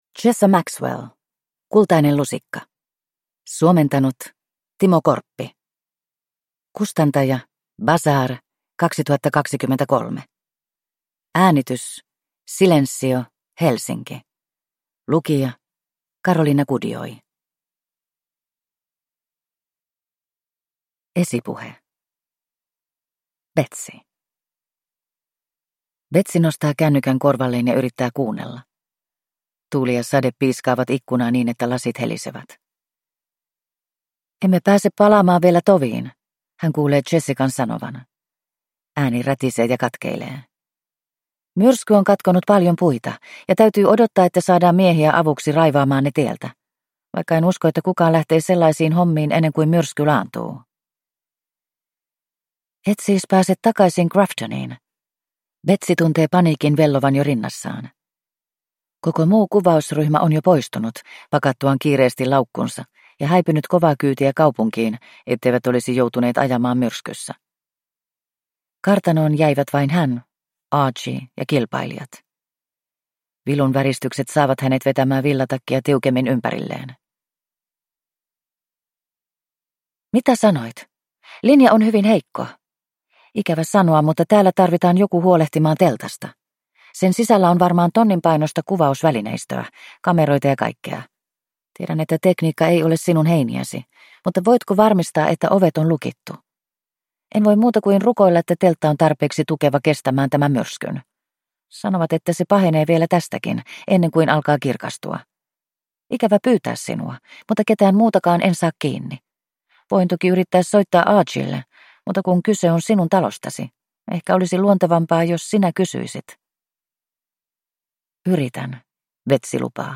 Kultainen lusikka – Ljudbok – Laddas ner